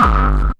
HD BD 15  -R.wav